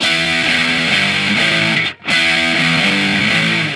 Guitar Licks 130BPM (9).wav